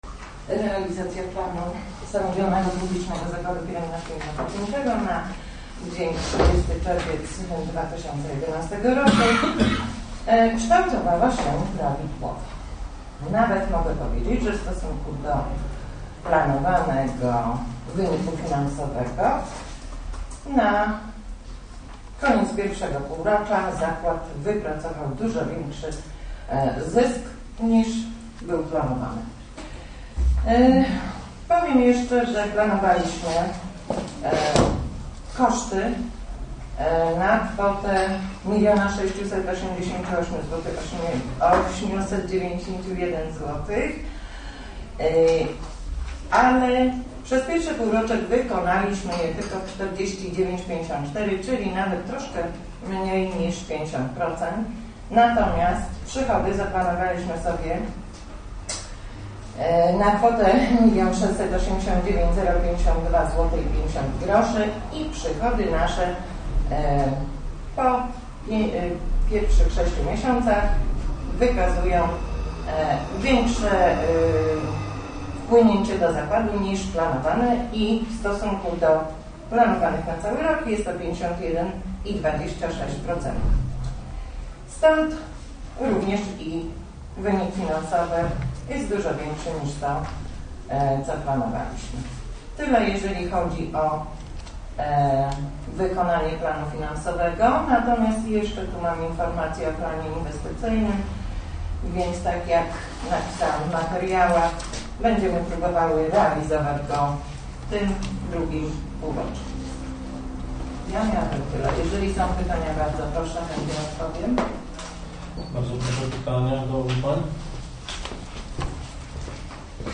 Po pierwszym półroczu jest on znacznie wyższy i wynosi 29.173,70zł. Na XXI posiedzeniu Zarządu Powiatu